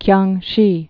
(kyängshē)